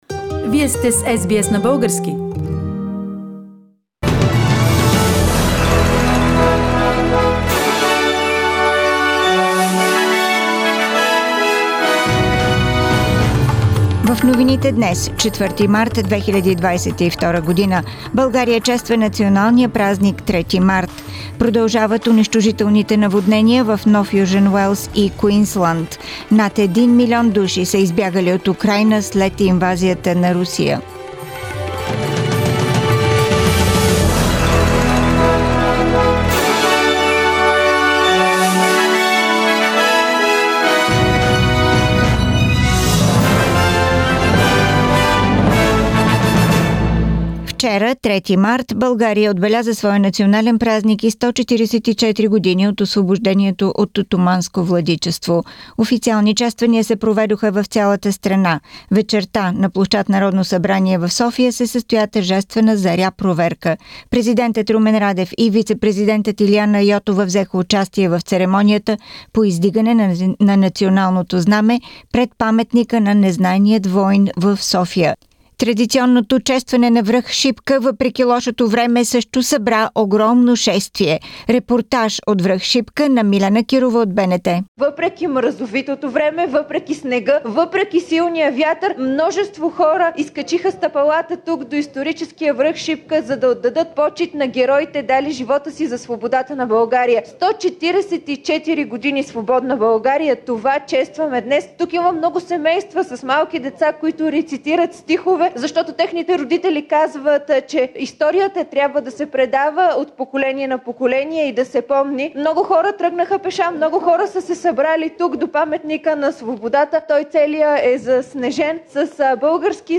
Weekly Bulgarian News – 4th March 2022